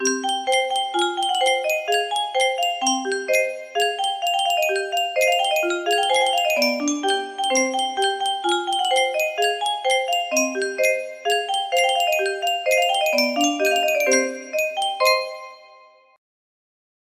Clone of Yunsheng Spieluhr - Synneshallingen 5592 music box melody